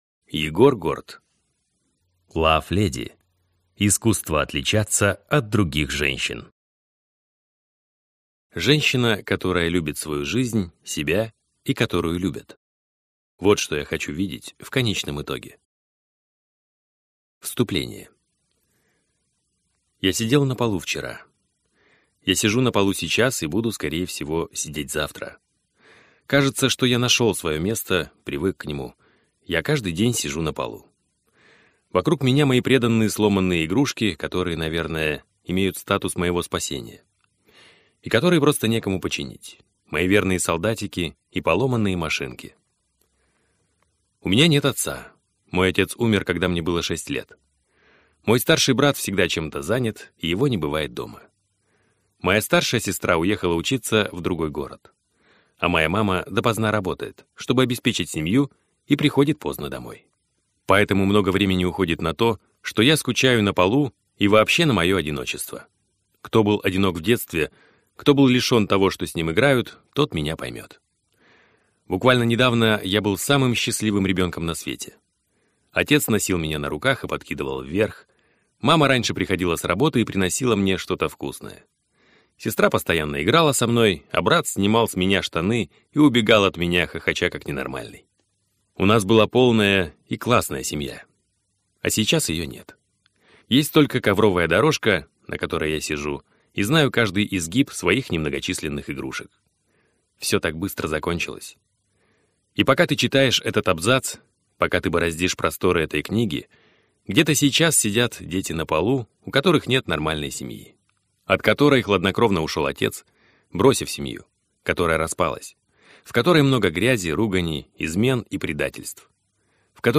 Аудиокнига LOVE-леди. Искусство отличаться от других женщин | Библиотека аудиокниг